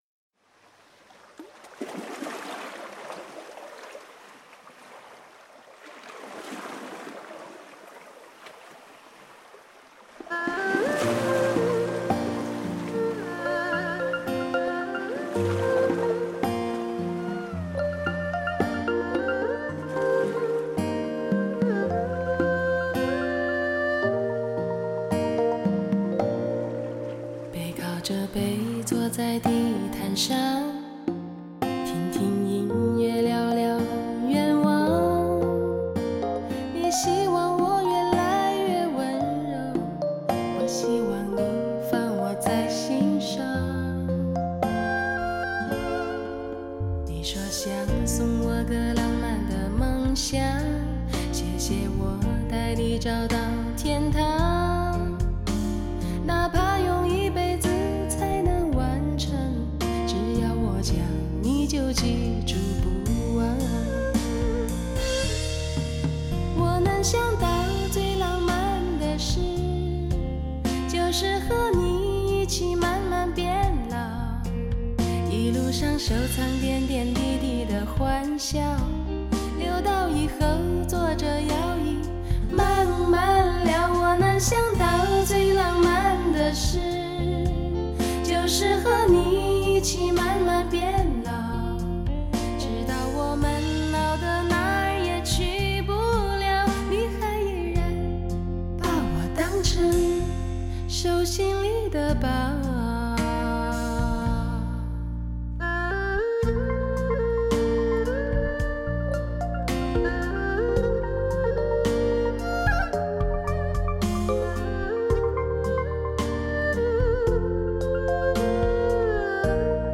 新世代的DTS-ES动态环绕声效，再次成为人声的超凡卓越
首席浓情浪漫歌伶，新时代罕见的清丽嗓音。